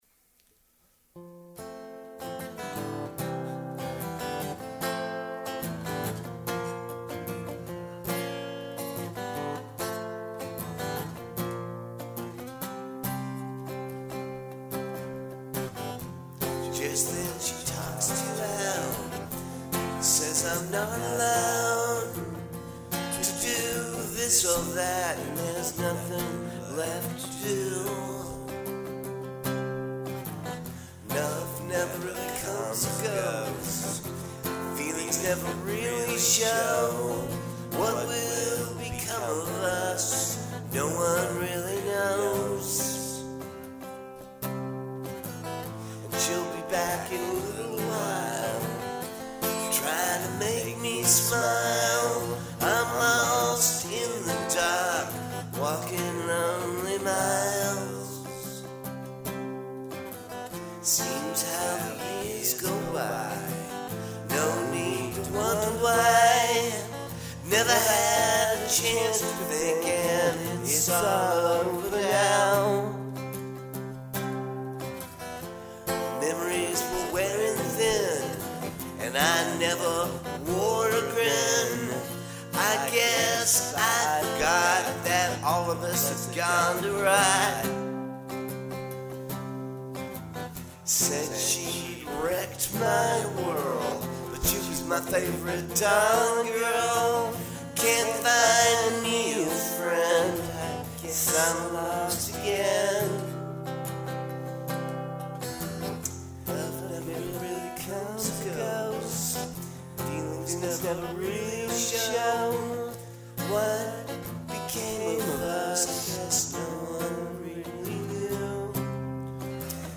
against my somber dirge version.
I do not use a metronome or click track when recording.